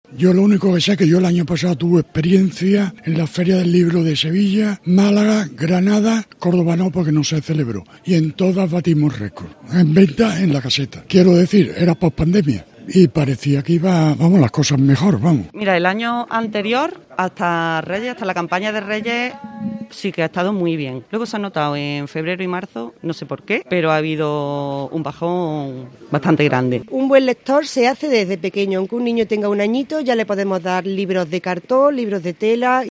Escucha a los libreros en el primer día de la Feria del Libro en el Bulevar Gran Capitán